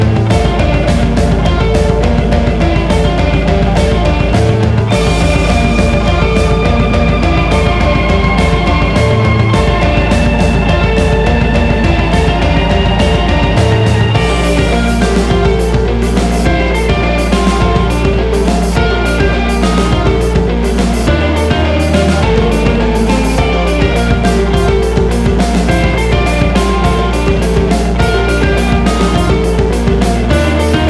une musique pop aux contours complexes